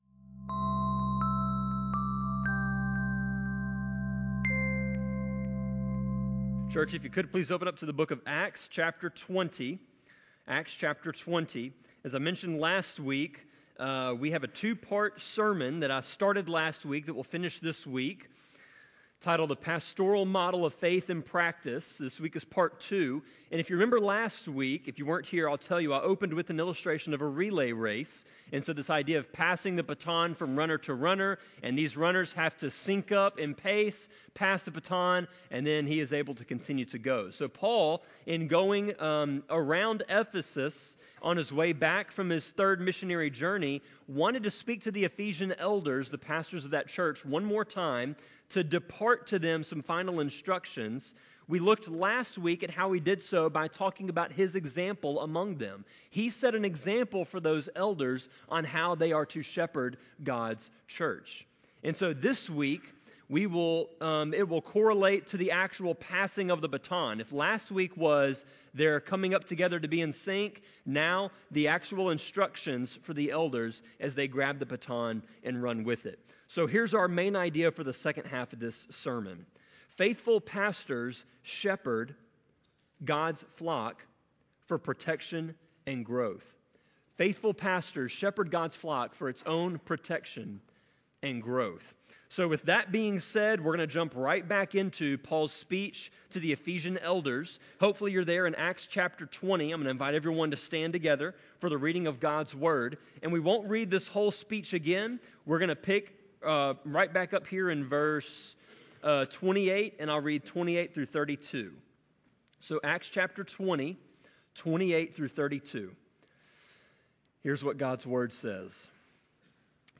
Sermon-24.9.15-CD.mp3